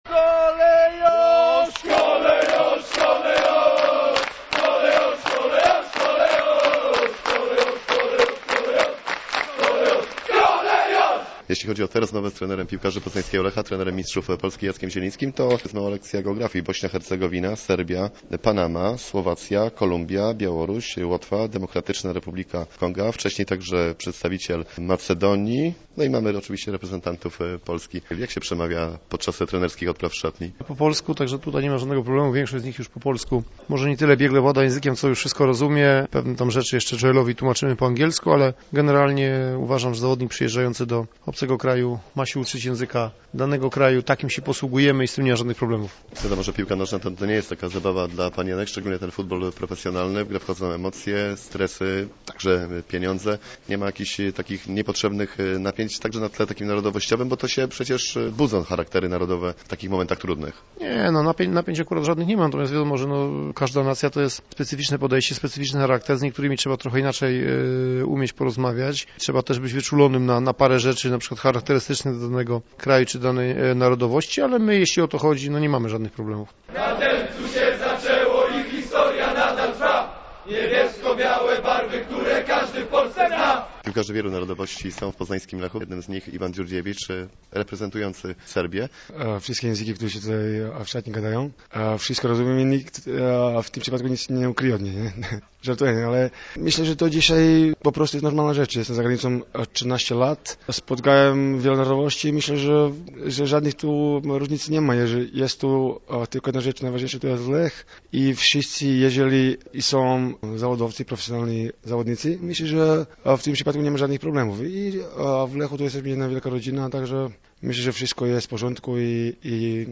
Nagroda za reportaż o "Kolejorzu"